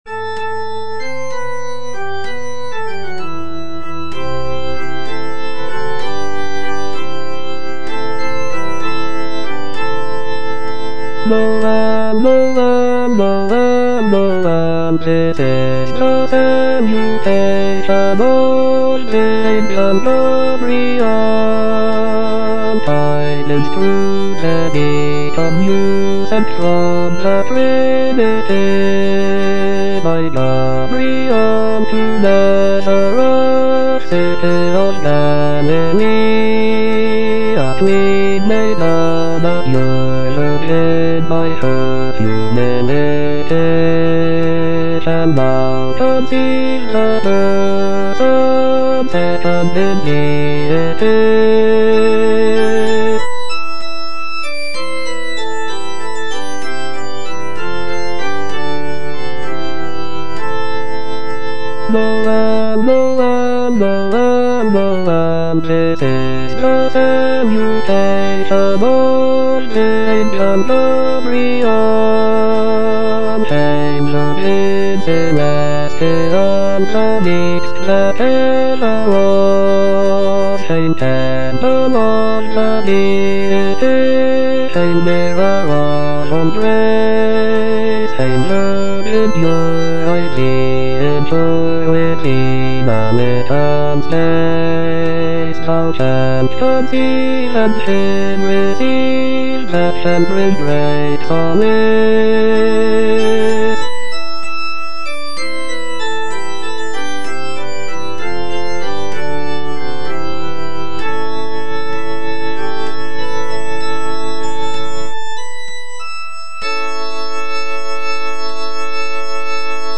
Bass (Voice with metronome) Ads stop
Christmas carol arrangement